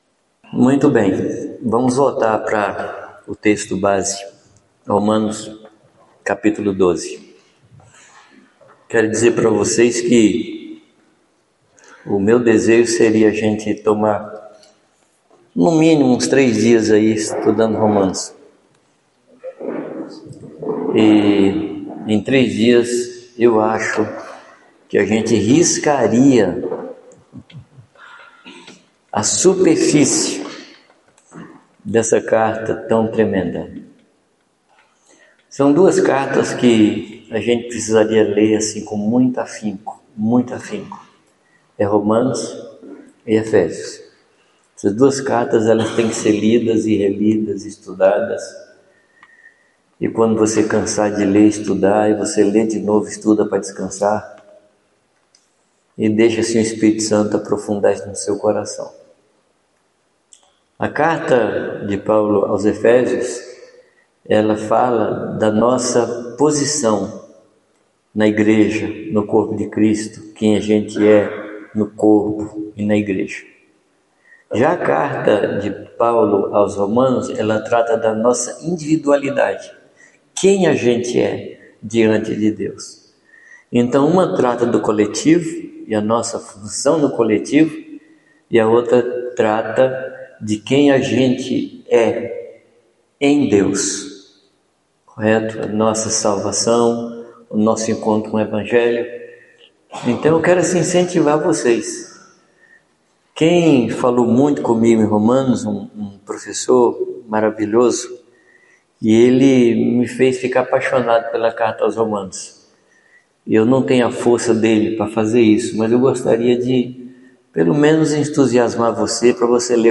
Palavra ministrada
no Encontro de Pastores